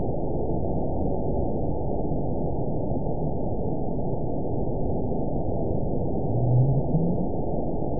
event 912286 date 03/23/22 time 18:50:19 GMT (3 years, 1 month ago) score 9.54 location TSS-AB02 detected by nrw target species NRW annotations +NRW Spectrogram: Frequency (kHz) vs. Time (s) audio not available .wav